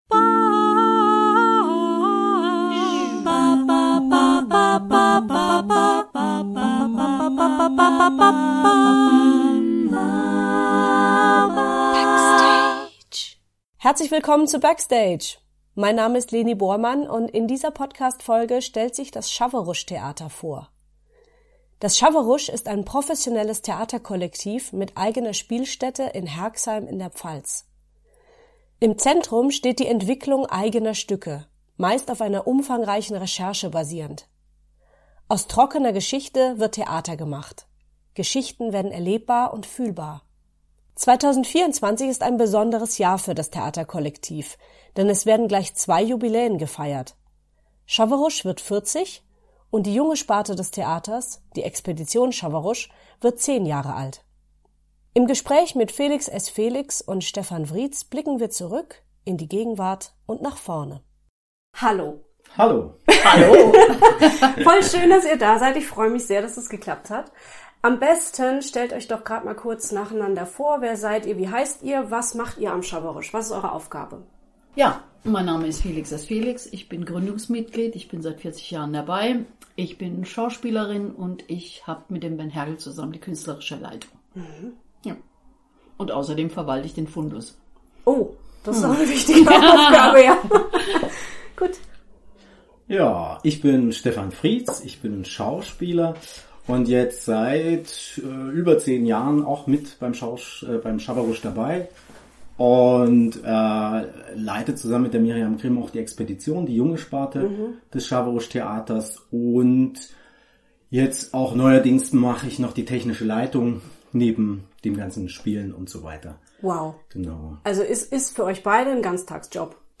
Aus trockener Geschichte wird Theater gemacht, Geschichten werden erlebbar und fühlbar. 2024 ist ein besonderes Jahr für das Theaterkollektiv, denn es werden gleich zwei Jubiläen gefeiert: Chawwerusch wird 40 und die junge Sparte des Theaters, die Expedition Chawwerusch, wird 10 Jahre alt. Im Gespräch